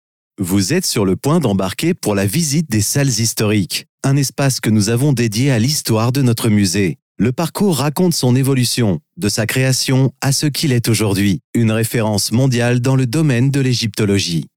Naturelle, Enjouée, Polyvalente, Mature, Amicale
Guide audio